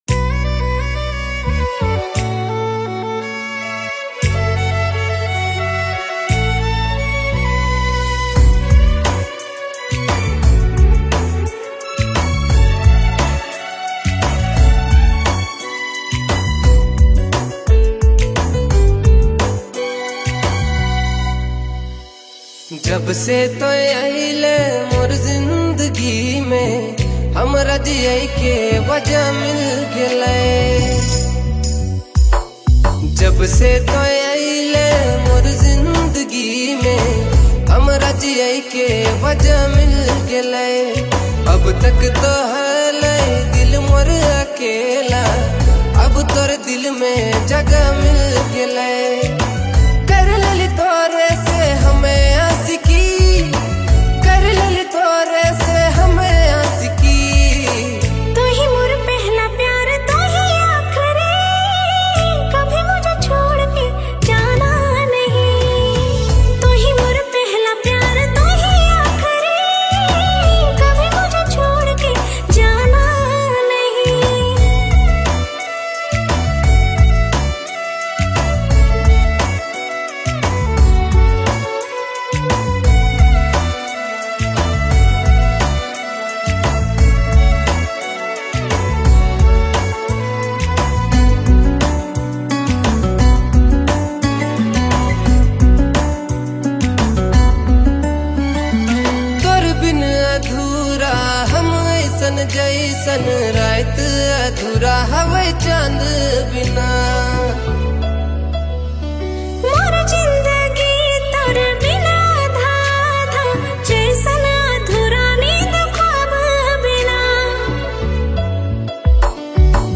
Dj Remixer
February Months Latest Nagpuri Songs